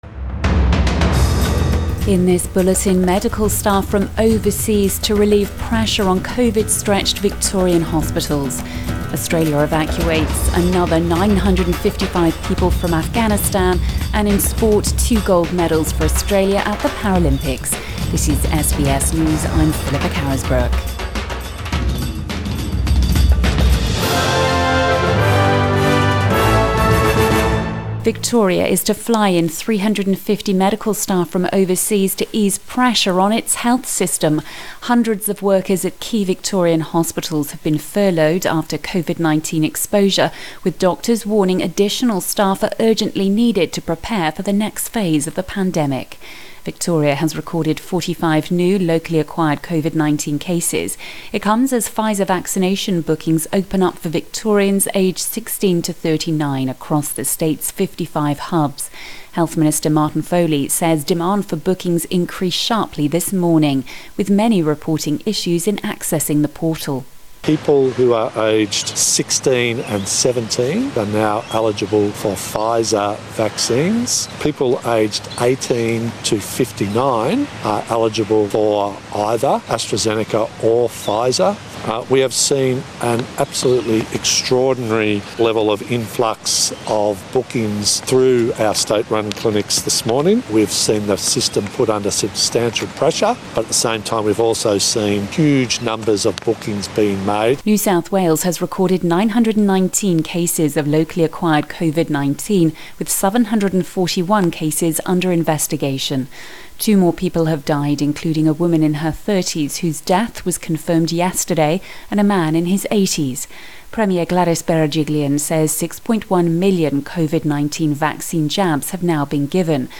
PM bulletin 25 August 2021